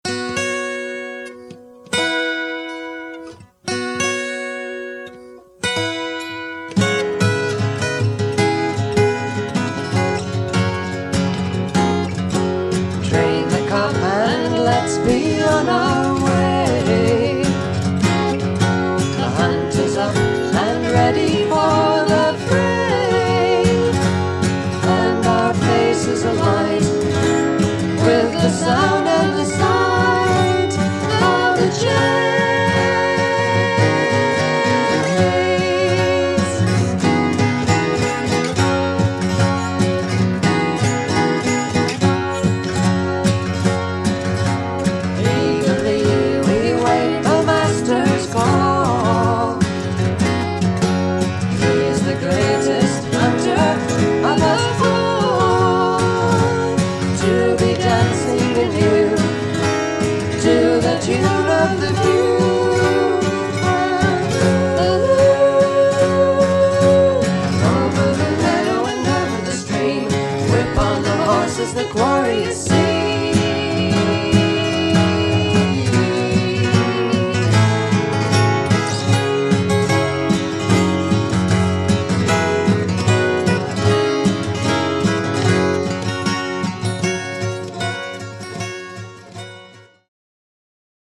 Recorded 1971 in San Francisco and Cambria, USA
remastered from the original tapes